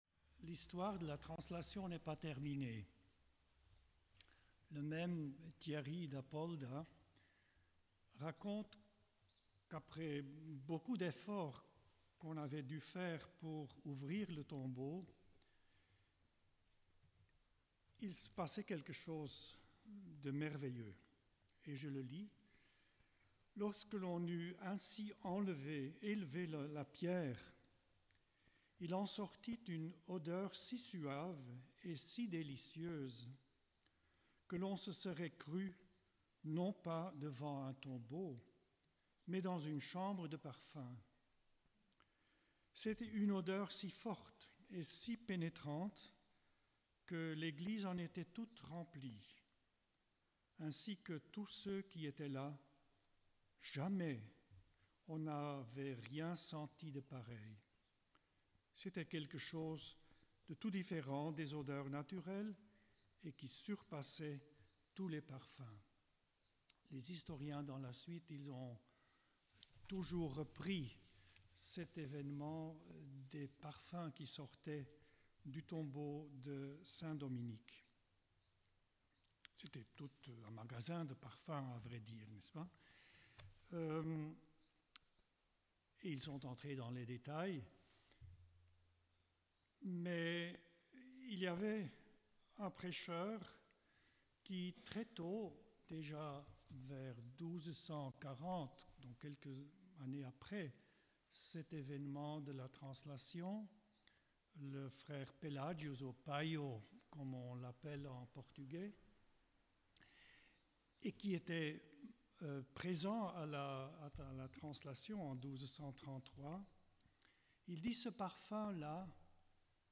Enregistrement : l'homélie
Nous vous proposons un enregistrement en direct de l'homélie